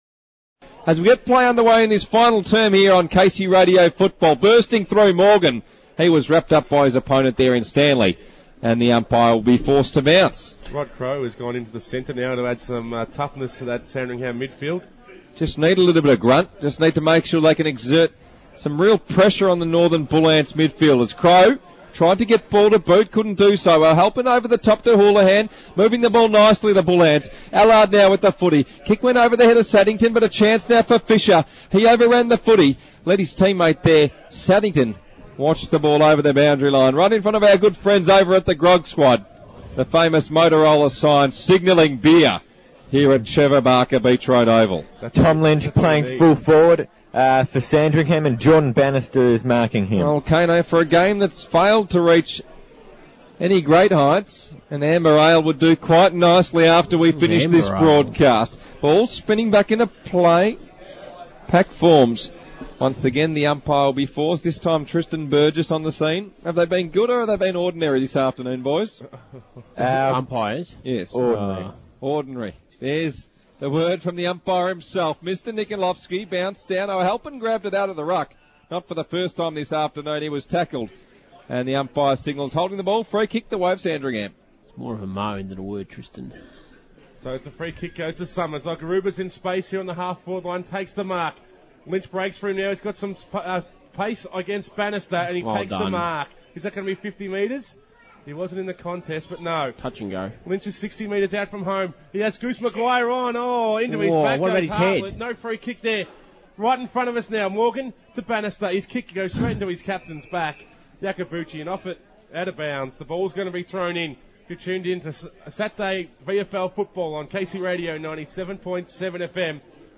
Download MP3 Final Quarter commentary.
Casey FM's coverage of the Round Five clash, Sandringham v Northern Bullants. Full coverage of the final quarter at Trevor Barker Oval, Sandringham.